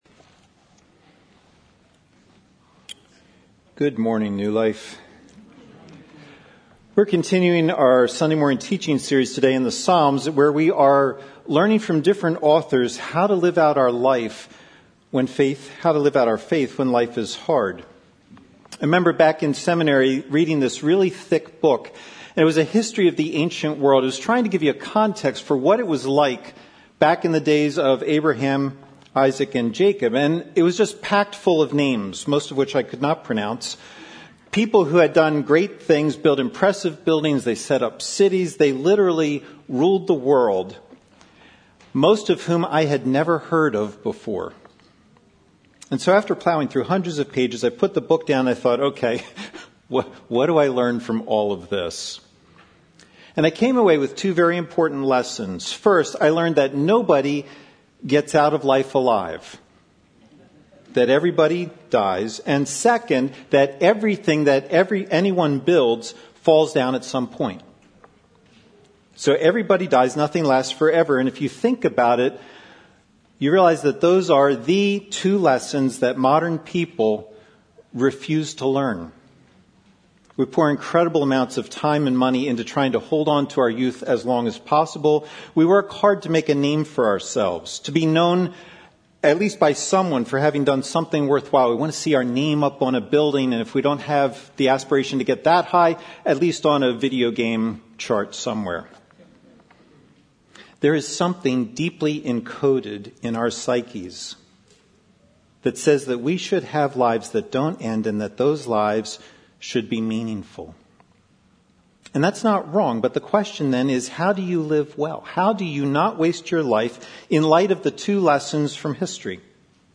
A message from the series " Meet the Psalmists."